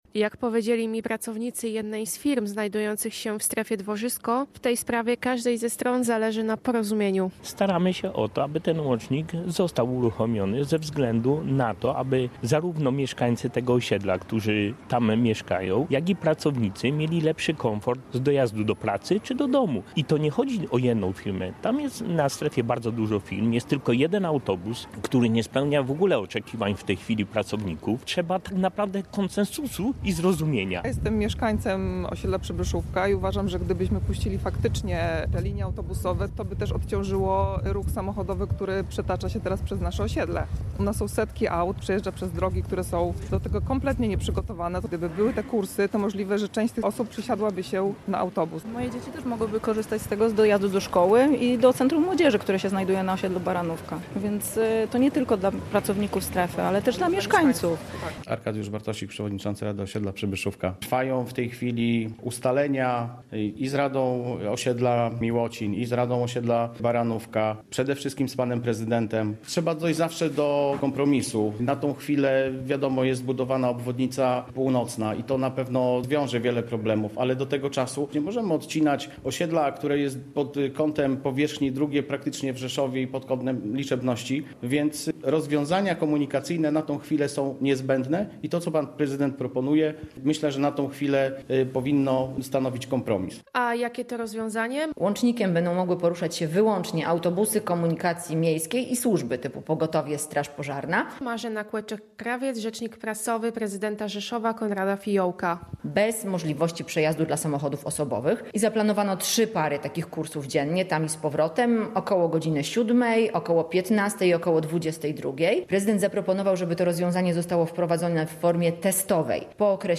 Mieszkańcy i miasto szukają kompromisu • Relacje reporterskie • Polskie Radio Rzeszów